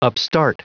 Prononciation du mot upstart en anglais (fichier audio)
Prononciation du mot : upstart